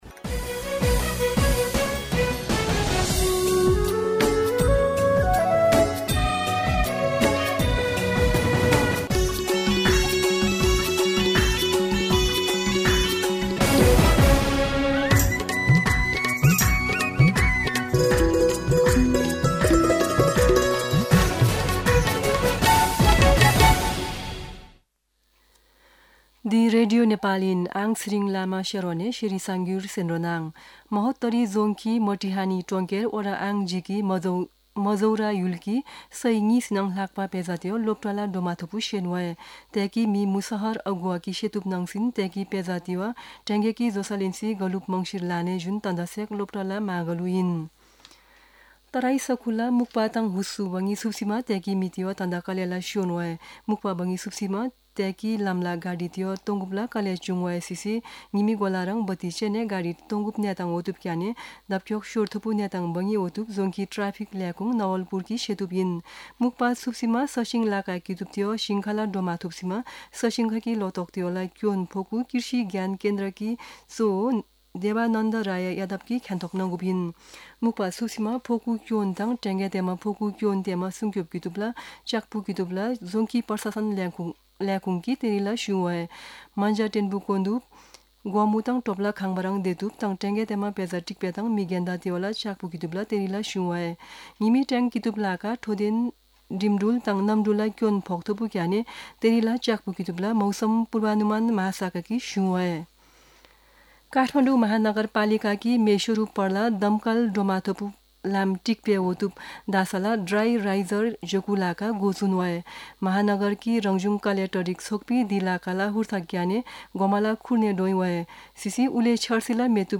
शेर्पा भाषाको समाचार : ६ माघ , २०८१
Sherpa-News-10-5.mp3